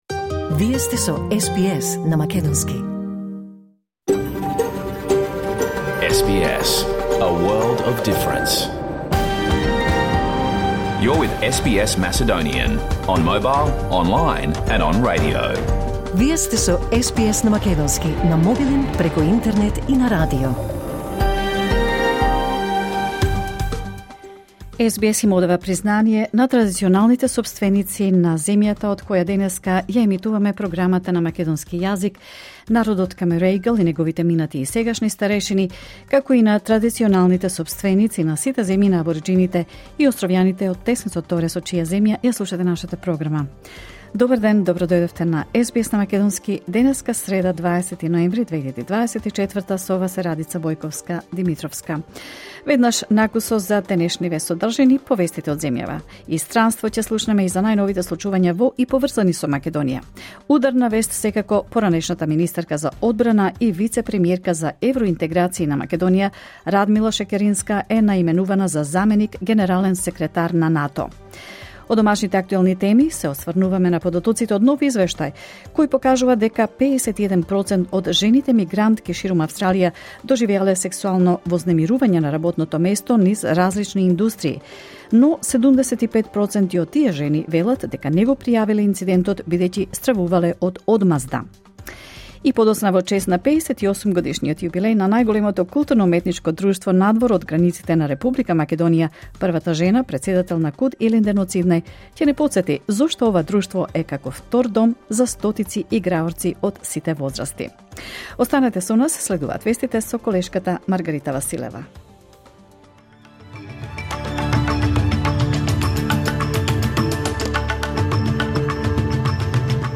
SBS Macedonian Program Live on Air 20 November 2024